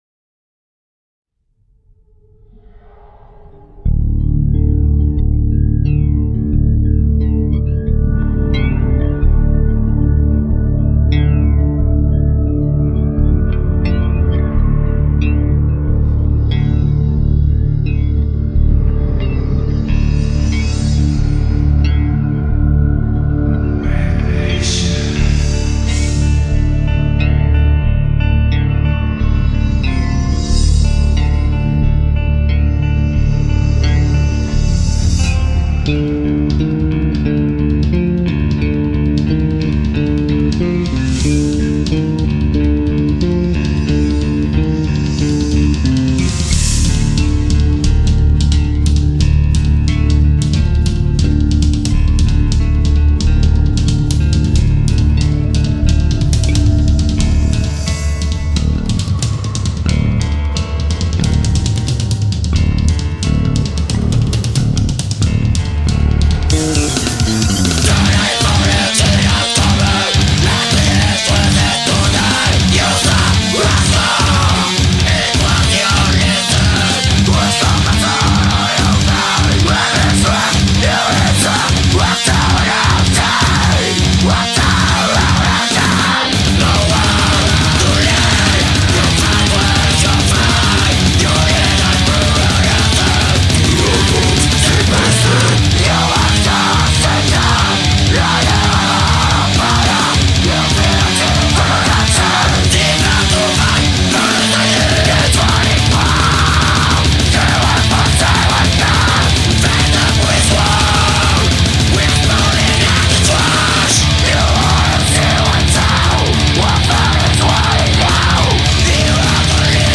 Du bon deathcore en veux-tu en voilà !